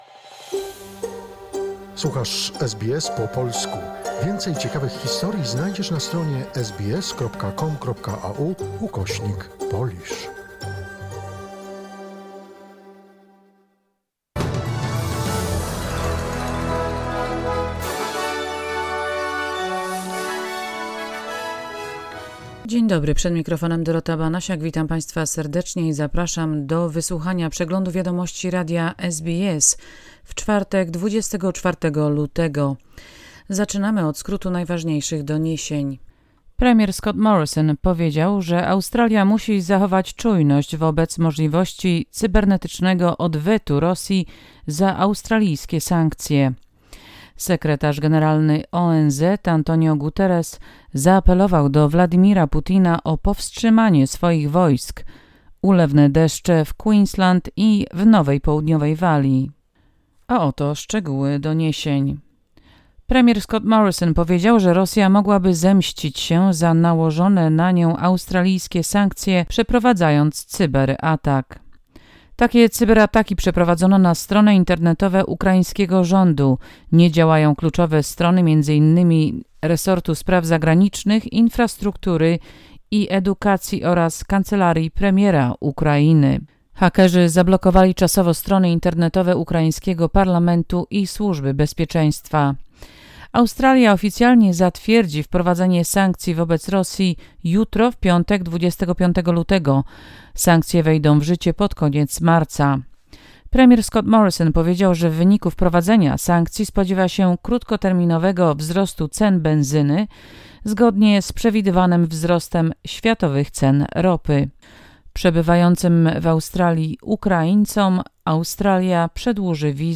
SBS News Flash in Polish, 24 February 2021